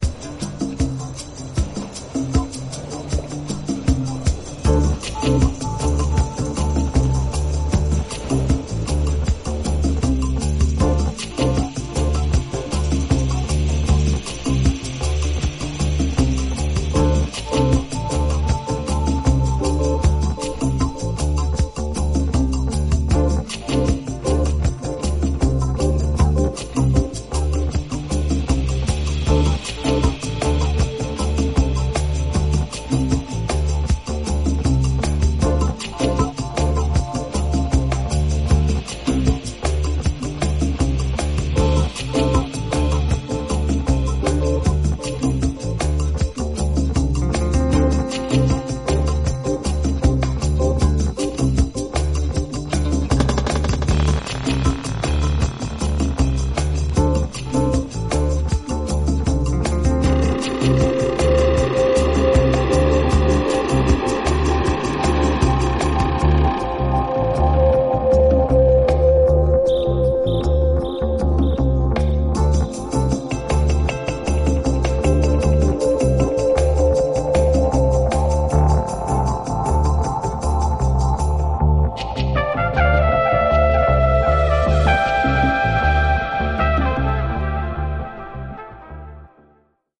ゆったりとしたパーカッションに揺らめくギター、トランペット、フルートが主旋律を奏でる